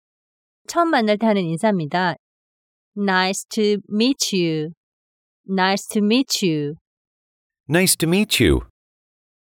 ㅣ나이스투 미이츄ㅣ